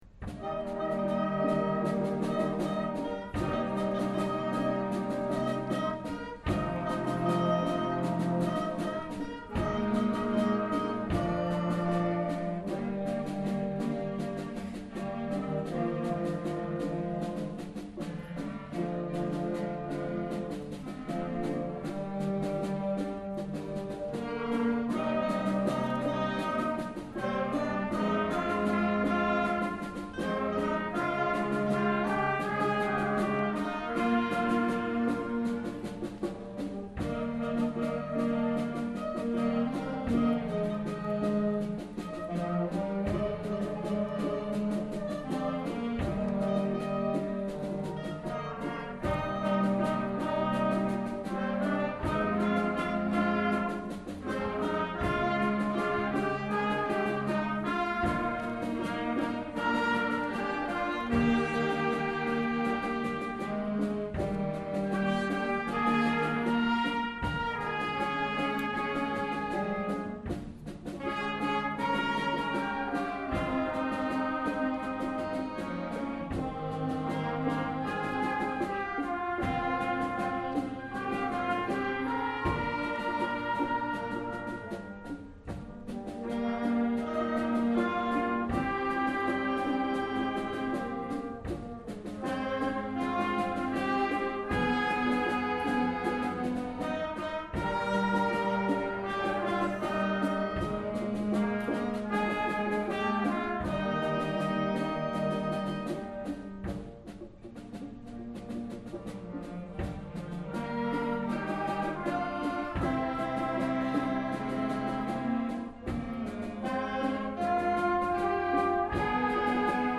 Circle of Life - Junior Wind